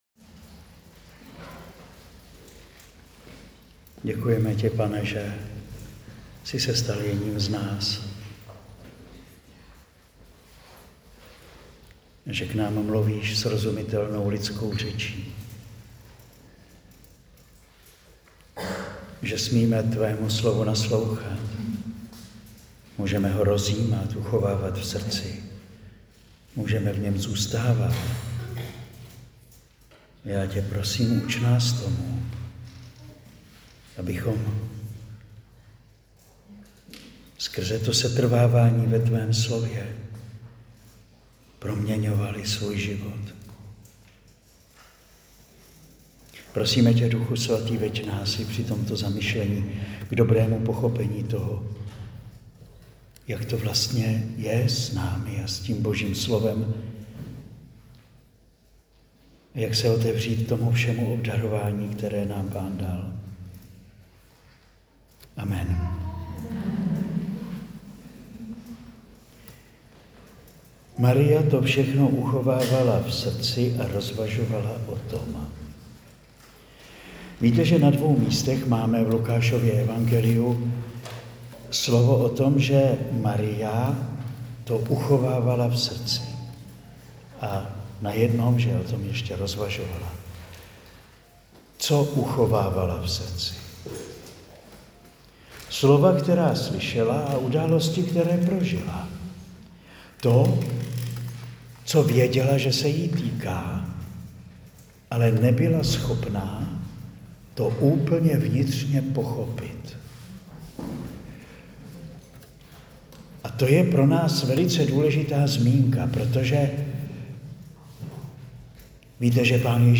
Přednáška zazněla na lednové duchovní obnově pro ženy v Želivi (2025).